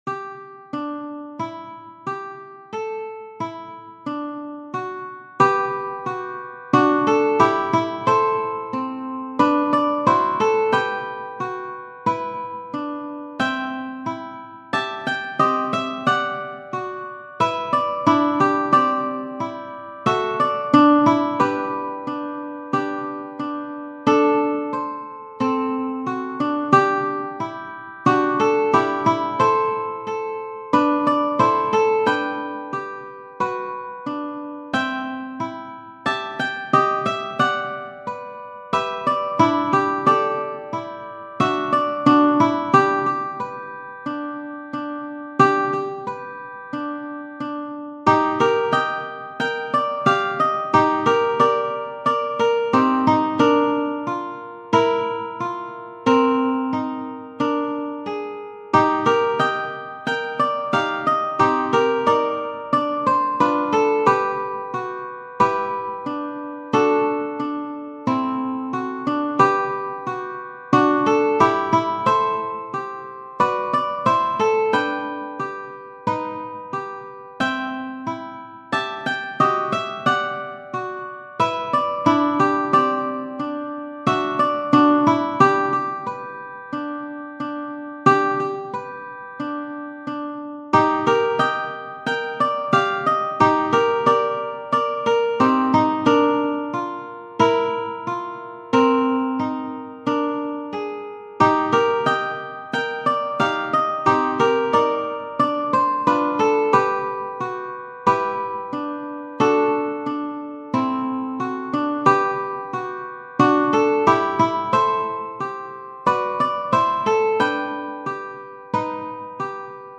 .mp3 File - Ukes 1 to 3 only
Smoke_Gets_In_Your_Eyes-3ukes.mp3